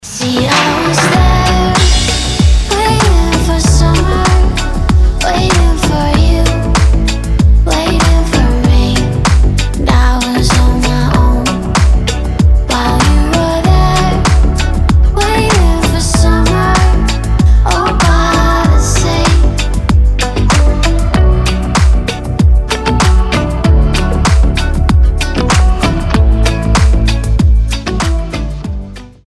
поп
Dance Pop
красивый женский голос
летние